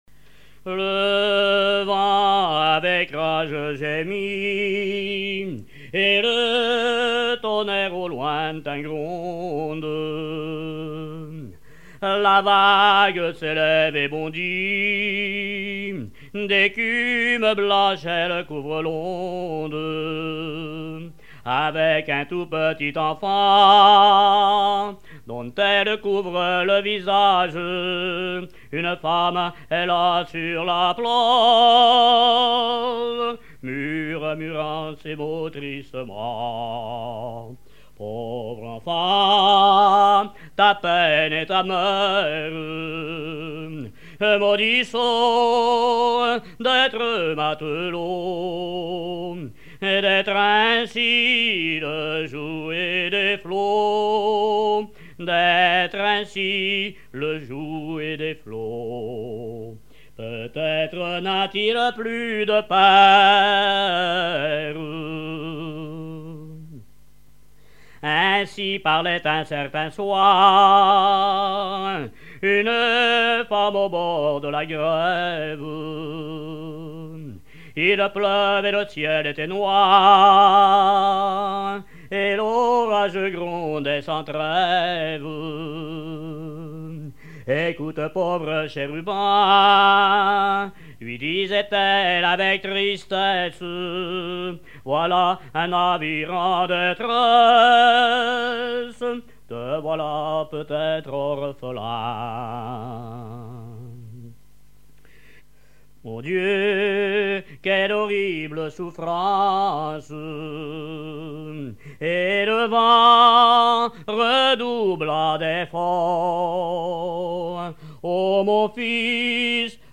Genre strophique
témoignages et chansons maritimes
Pièce musicale inédite